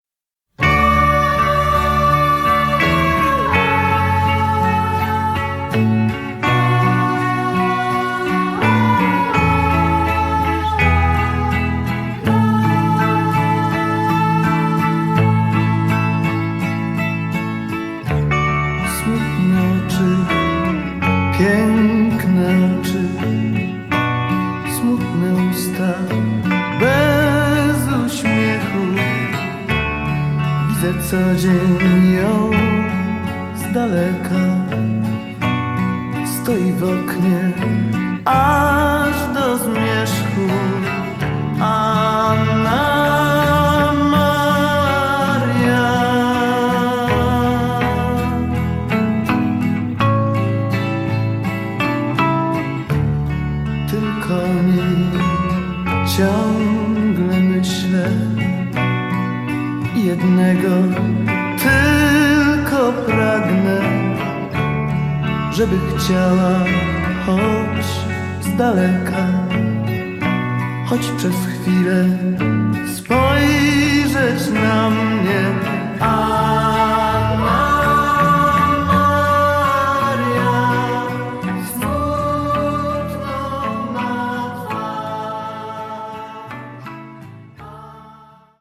DUET
VOC GUITAR KEYB BASS DRUMS TEKST